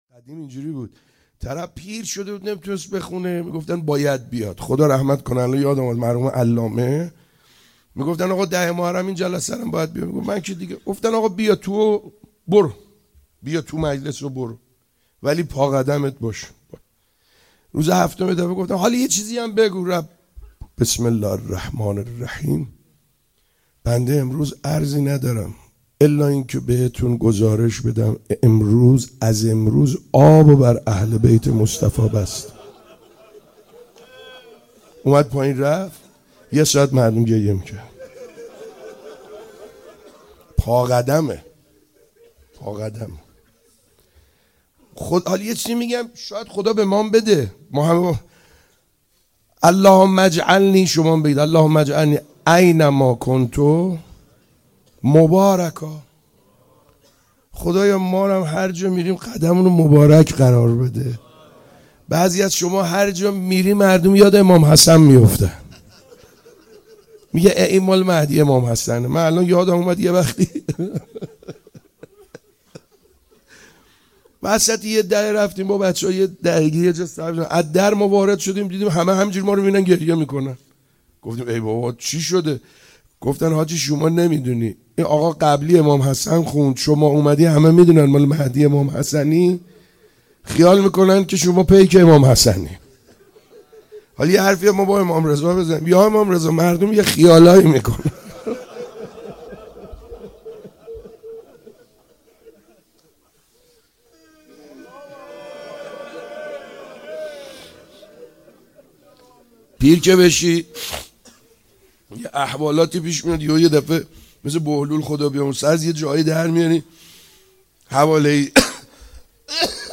روضه امام رضا ع